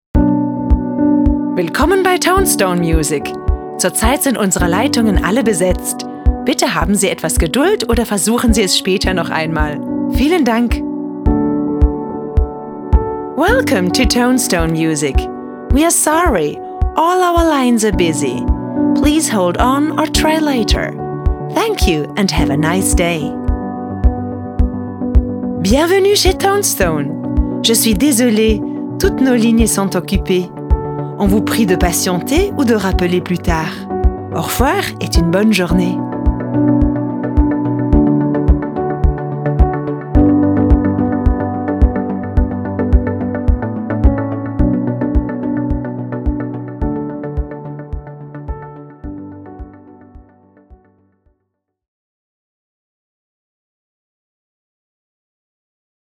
Hotline
ToneStone-Sprachaufnahmen.mp3